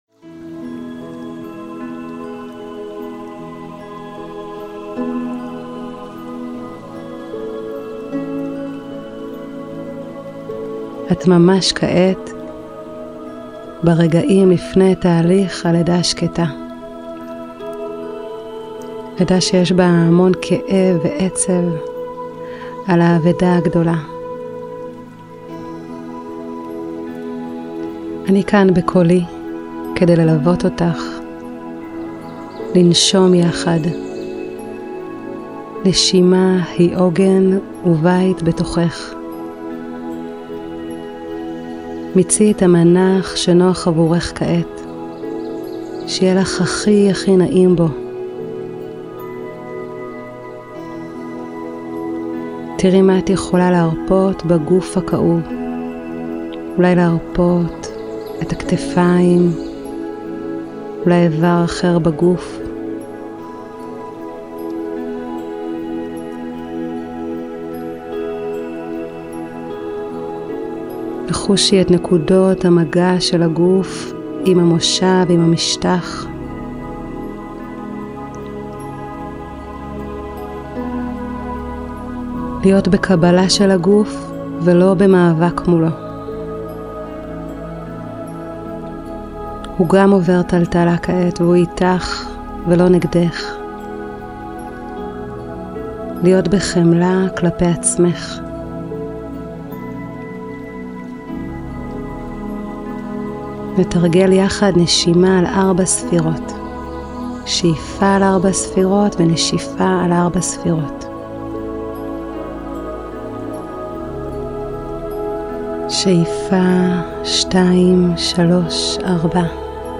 הנחייה קולית 2: לפני תהליך הלידה השקטה, למצוא לעצמך מקום לתרגול מיינדפולנס וחמלה עם הגוף ולא נגדו, עם חום הלב כלפי עצמך.